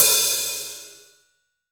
Index of /90_sSampleCDs/AKAI S6000 CD-ROM - Volume 3/Drum_Kit/ROCK_KIT1